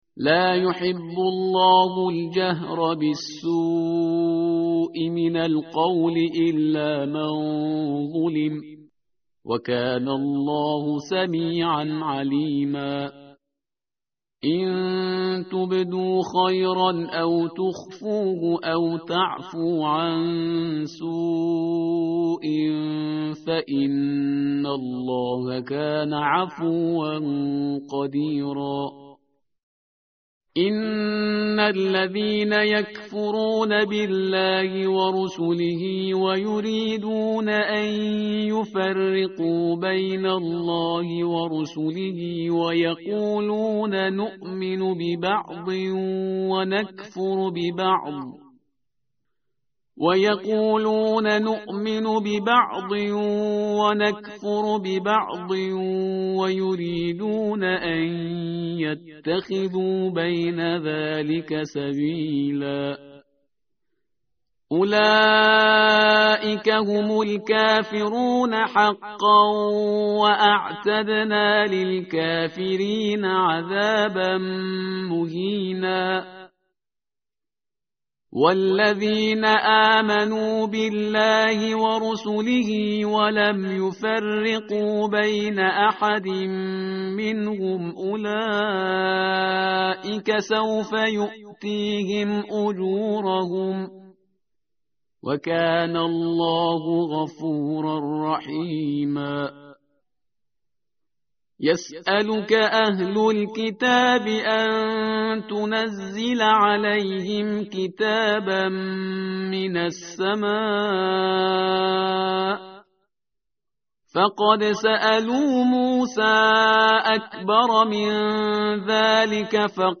tartil_parhizgar_page_102.mp3